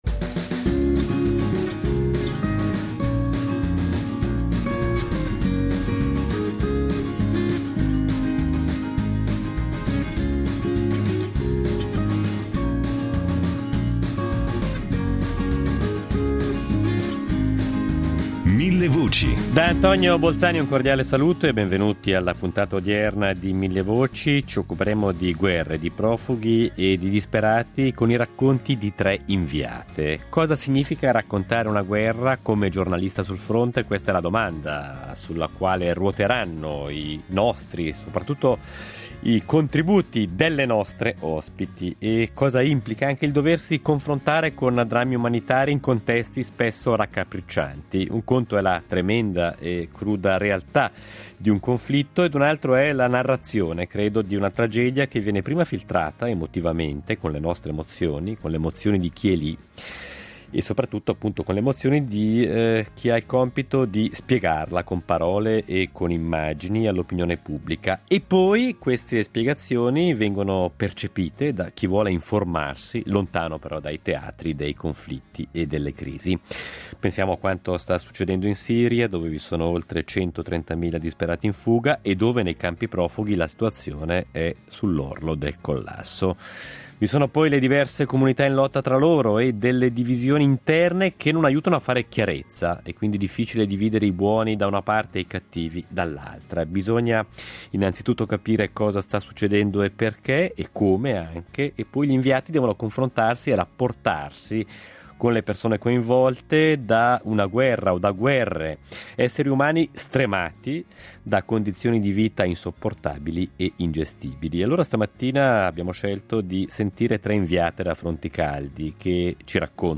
Stamattina tre inviate da fronti caldi ci raccontano la loro esperienza, tre testimonianze forti di tre coraggiose donne che ci aiutano a capire qual è la realtà di contesti e di zone dove i diritti umani sono spesso violati.